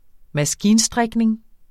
Udtale [ -ˌsdʁεgneŋ ]